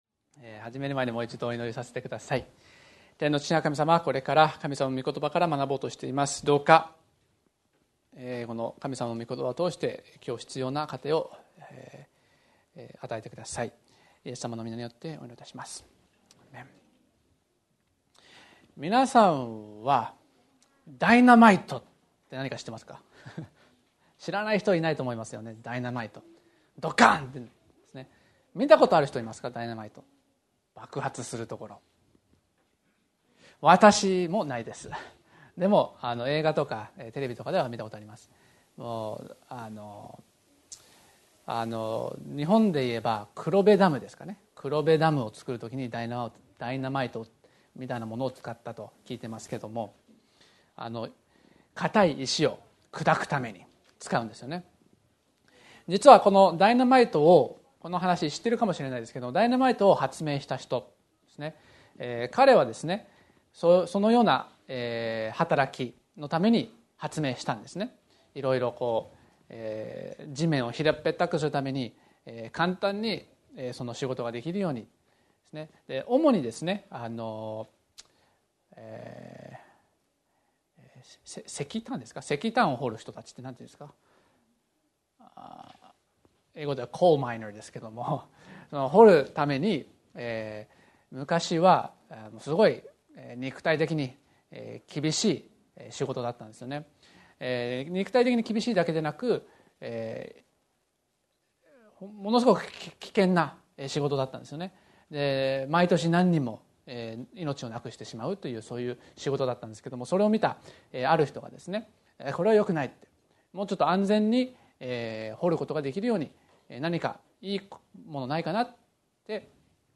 礼拝